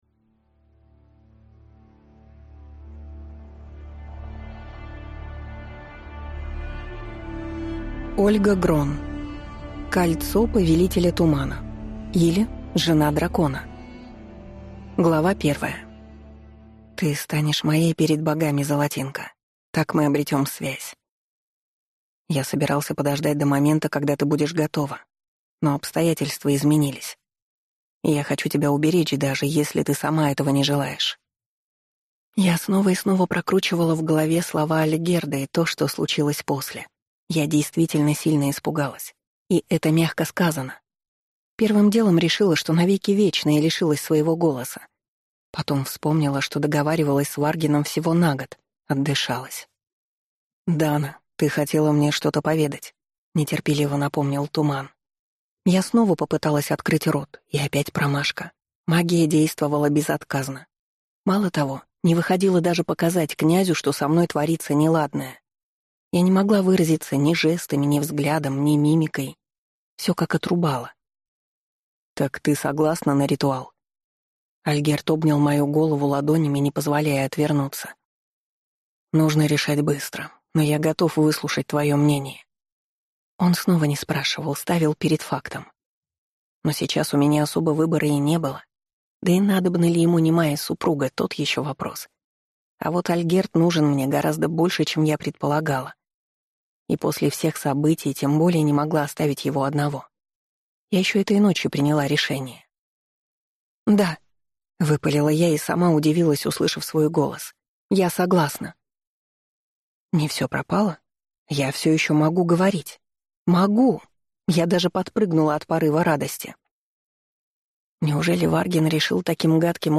Аудиокнига Кольцо повелителя тумана, или Жена дракона | Библиотека аудиокниг